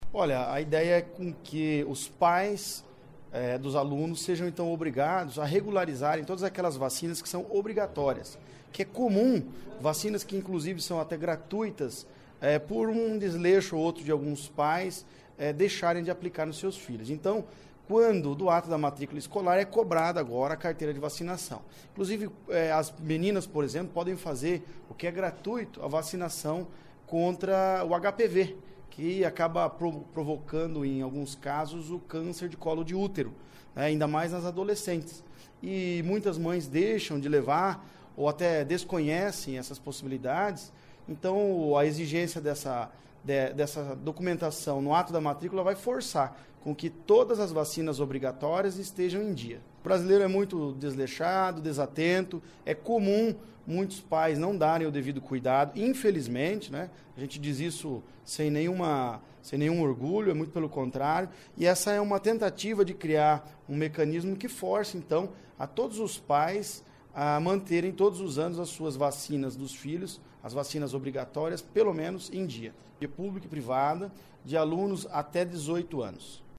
O projeto é do deputado Tião Medeiros (PTB) e vai obrigar os pais a manterem as carteiras de vacinação em dia. Ouça entrevista com o deputado.
(Sonora)